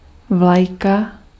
Ääntäminen
IPA: [vlɑχ]